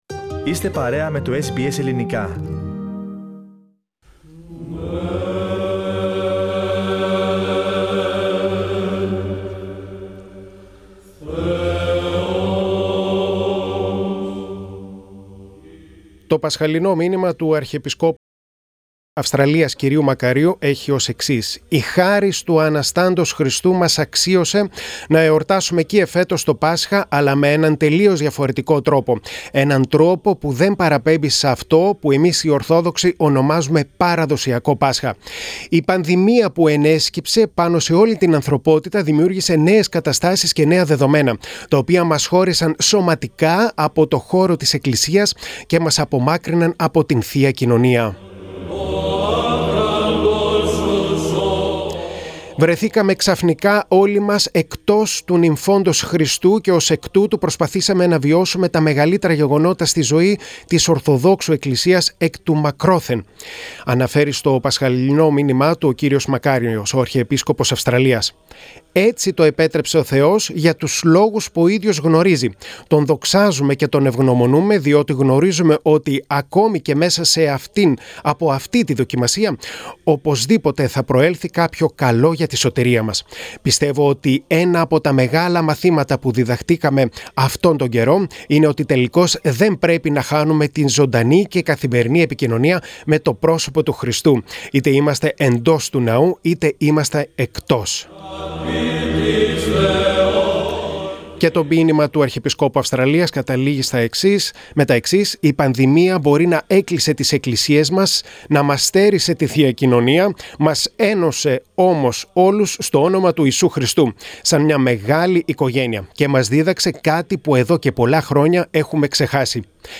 Η αγάπη δεν είναι ένα απλό συναίσθημα, στην πραγματικότητα είναι ένας τρόπος για να υπάρχουμε στον κόσμο και βοήθησε να το καταλάβουμε αυτό η πανδημία του κορωνοϊού, αναφέρει ο Αρχιεπίσκοπος Αυστραλίας κ, Μακάριος, στο μήνυμά τους με την ευκαιρία της φετινής Λαμπρής.